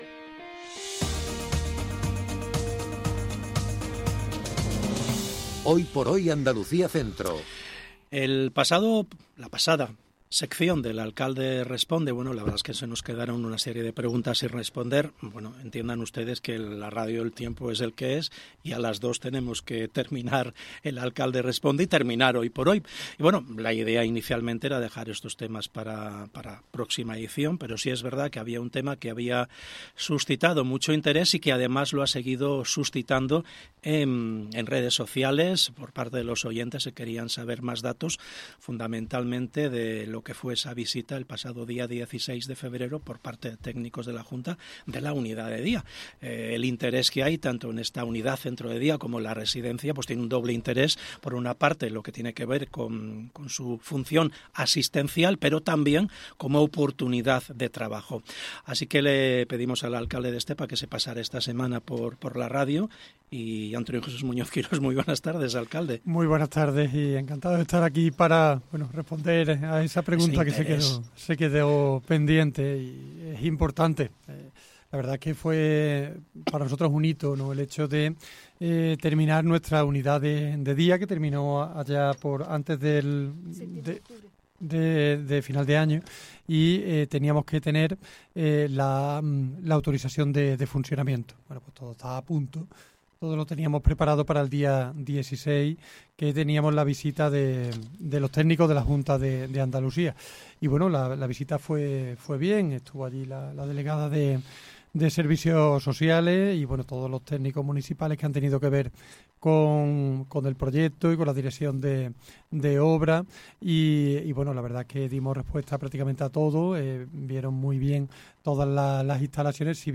ENTREVISTA ANTONIO J MUÑOZ & ASUNCION LLAMAS - Andalucía Centro
Antonio Jesús Muñoz Quirós, alcalde de Estepa, y Asunción Llamas Rengel, delegada municipal de Servicios Sociales, han explicado en Hoy por Hoy Andalucía Centro como transcurrió la visita de los técnicos de la Junta de Andalucía